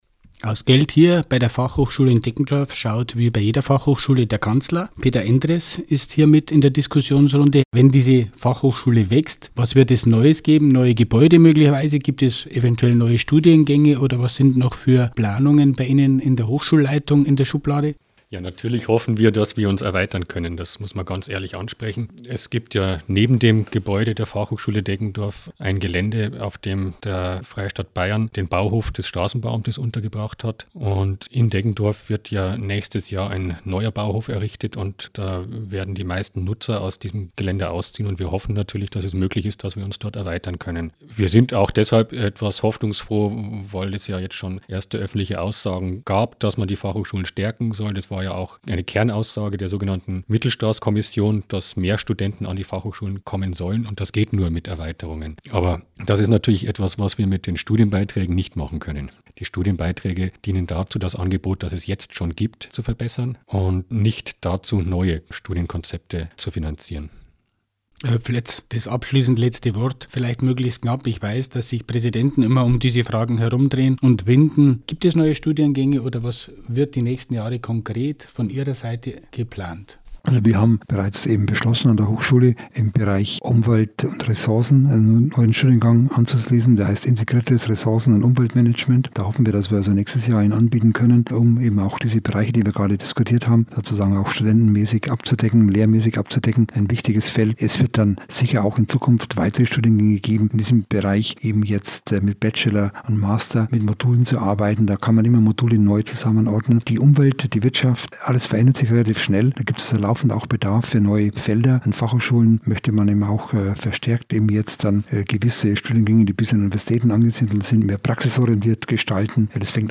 Diskussionsrunde: FH-Erweiterung